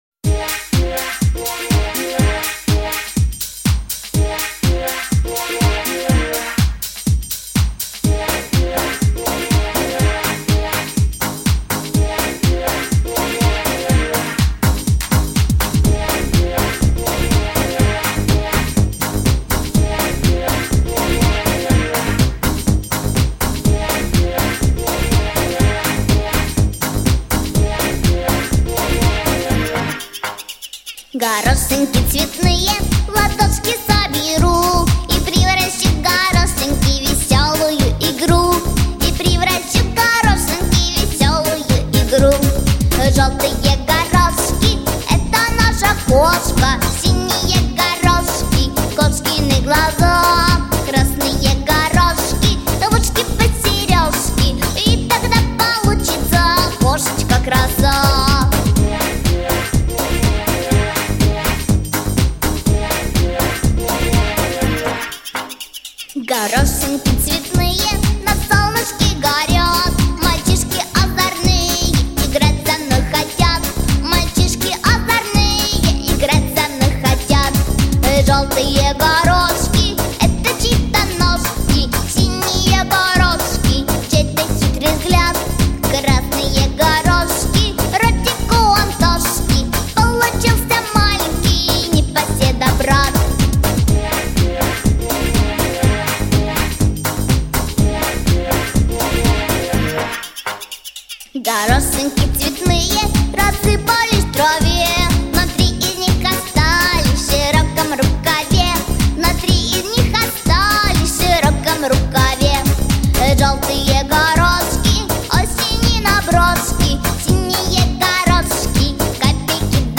Песенка для танца